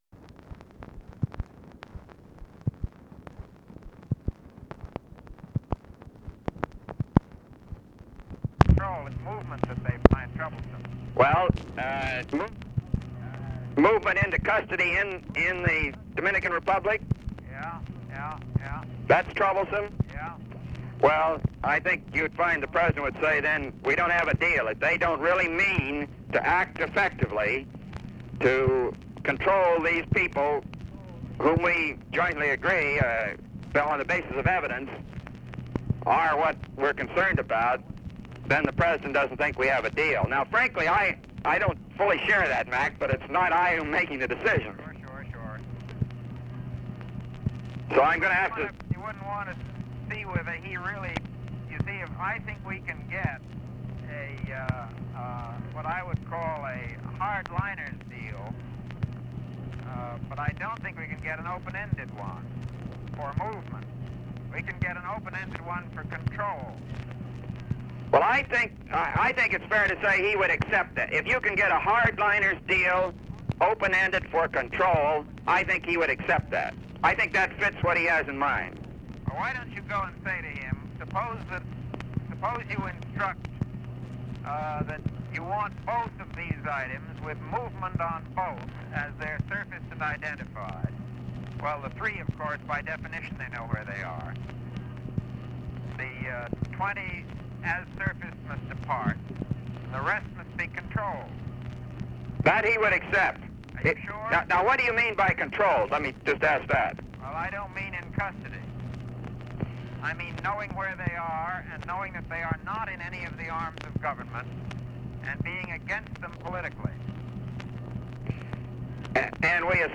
Conversation with MCGEORGE BUNDY, May 23, 1965
Secret White House Tapes